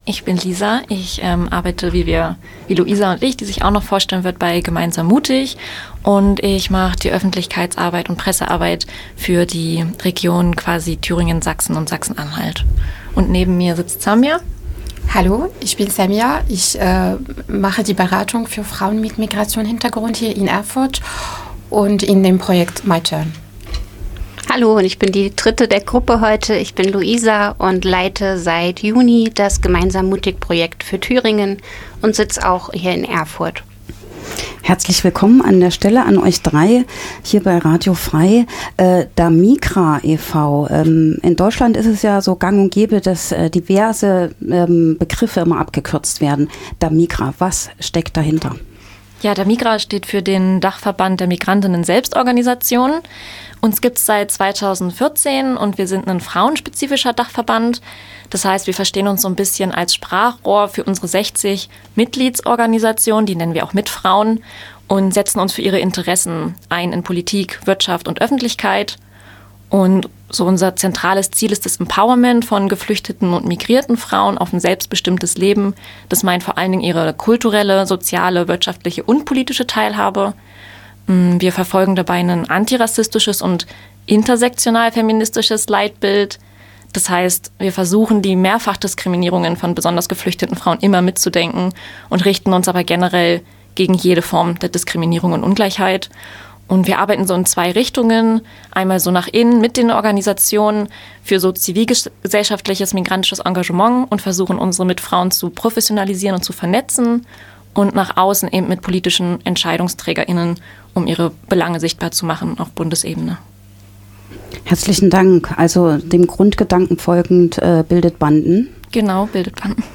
| Interview mit DaMigra
Wie Beratung ganz praktisch aussieht, welche Herausforderungen es gibt und was das Arbeitsfeld für sie bedeutet, erzählen die drei Frauen im Interview.